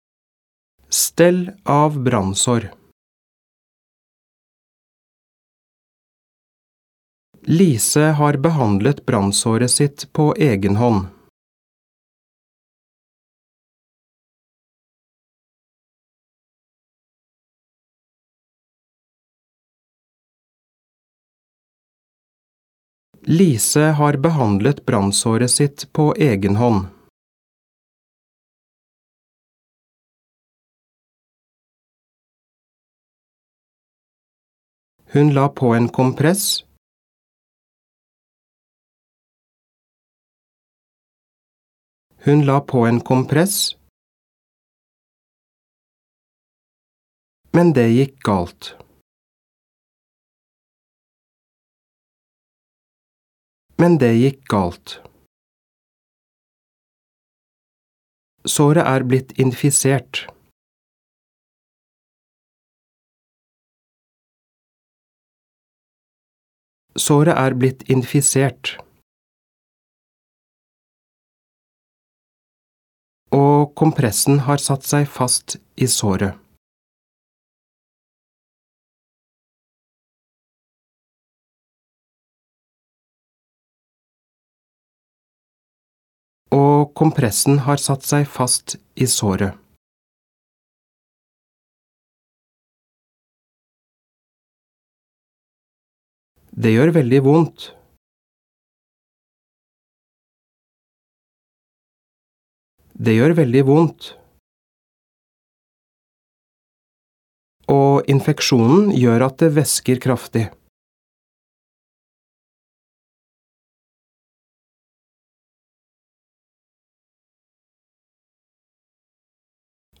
Diktat leksjon 4
• Andre gang leses hele setninger og deler av setninger.